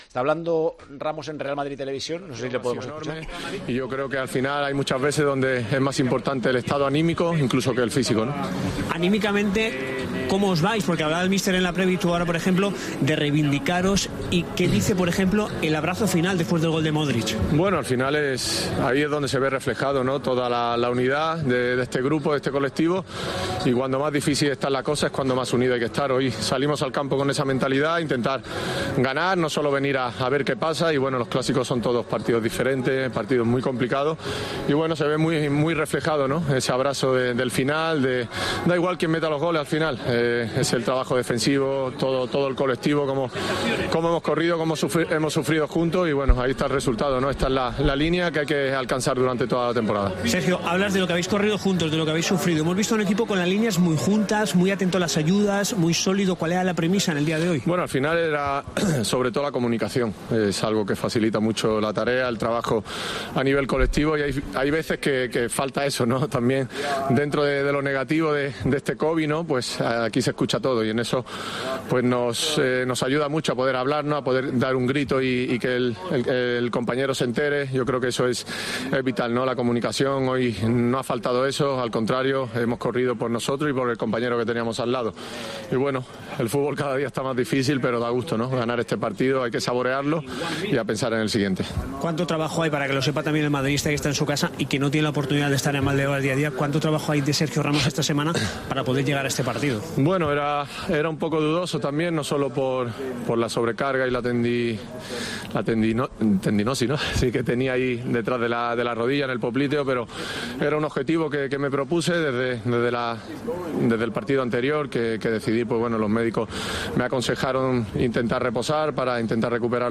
"Cuanto más difícil están las cosas, es cuando más unido tienes que estar", apuntó Sergio Ramos en RealmadridTV al término de la victoria del Madrid en el Camp Nou.